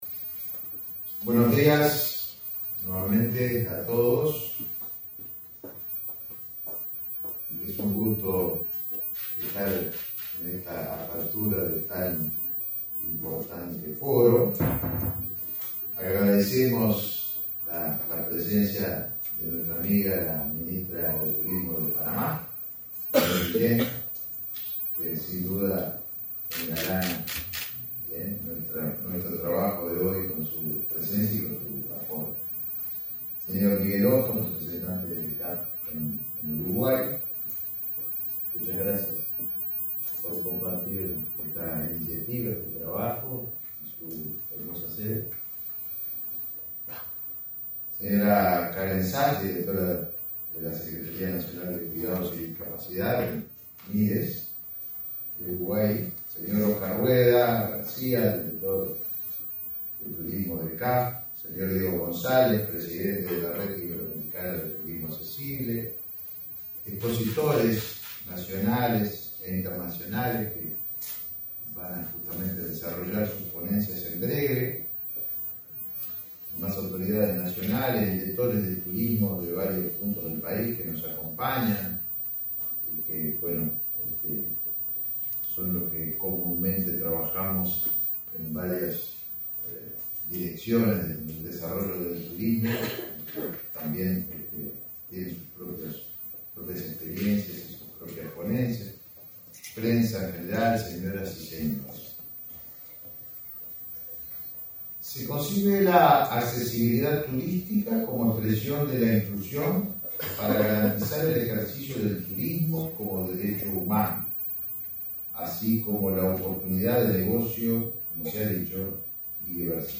Acto por el Foro de Turismo Inclusivo